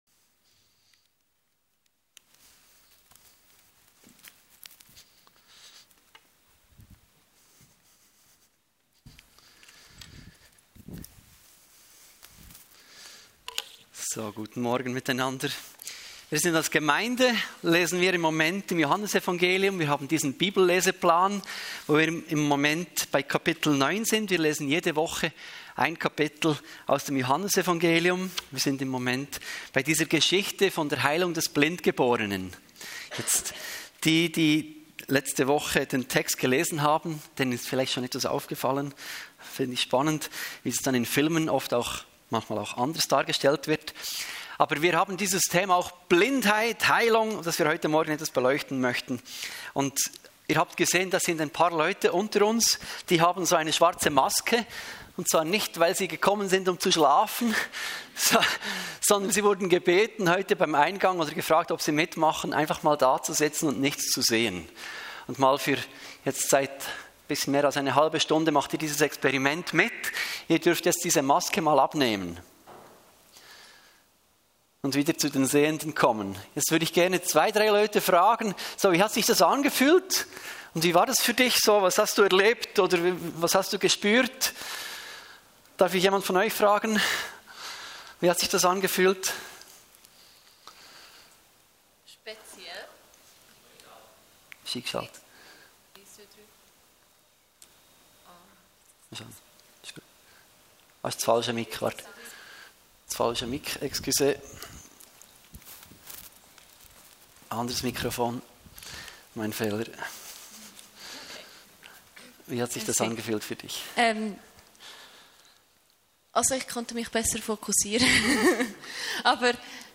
Talk zu künstlicher Intelligenz – FMG Frutigen – Podcast